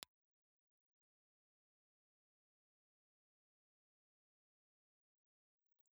Impulse Response file of the STC 4031J Ball and Biscuit microphone.on axis
STC_4021_Ball_Biscuit_OnAxis.wav
Impulse response files have been supplied with the microphone positioned horizontally (on axis) and vertically to the source.